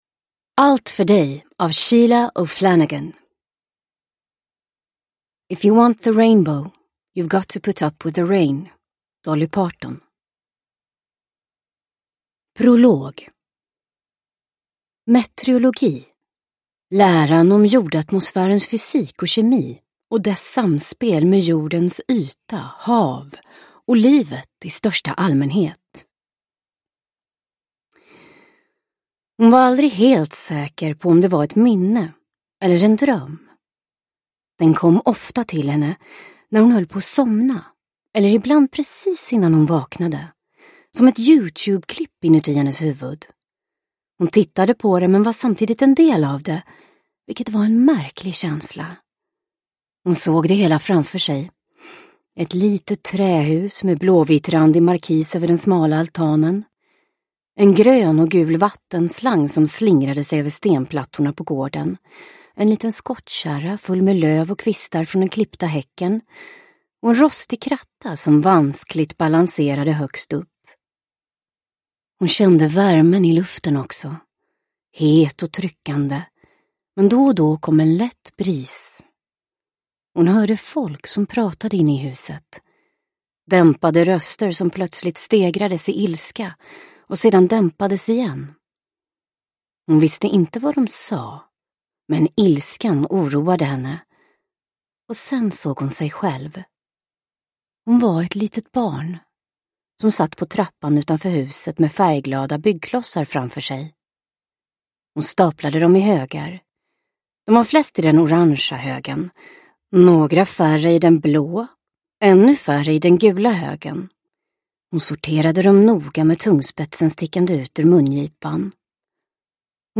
Allt för dig – Ljudbok – Laddas ner